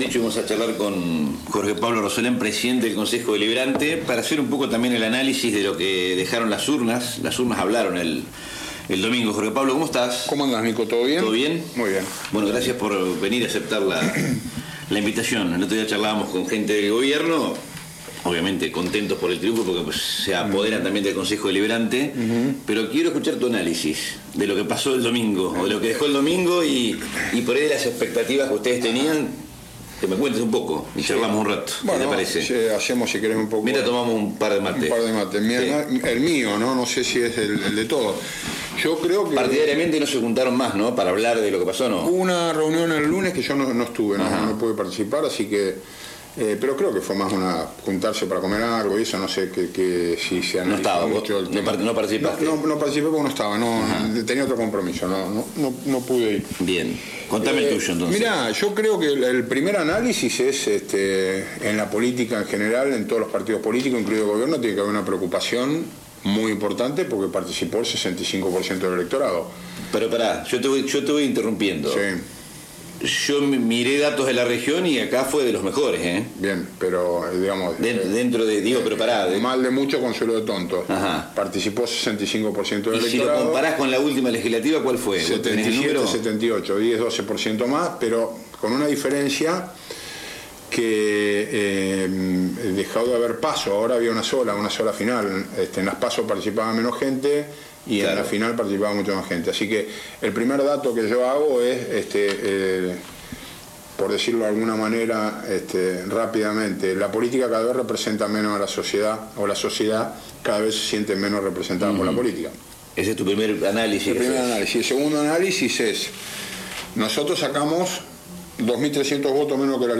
Jorge Pablo Rosolen, presidente del Concejo Deliberante, ofreció un análisis detallado de los resultados del acto eleccionario para concejales en Rivadavia. Lo hizo radialmente en LNP multimedios.